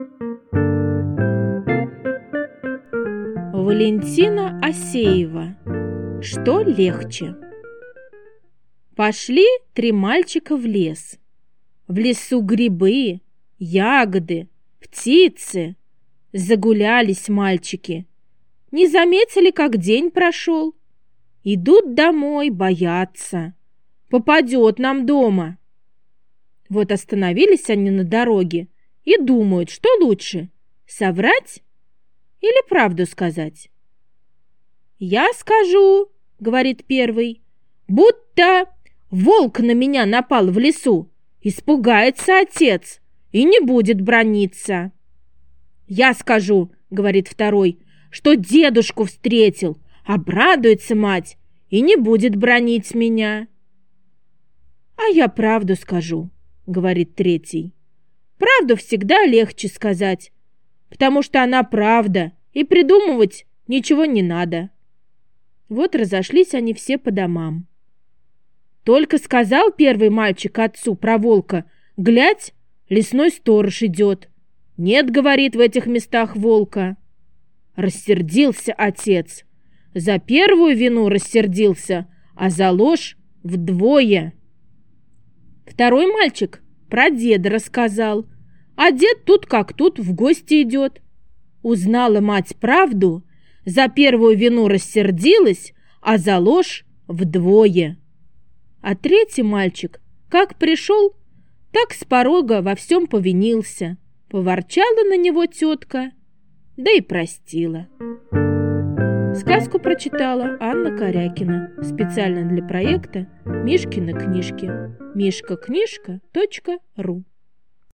Что легче? - Осеева - слушать рассказ онлайн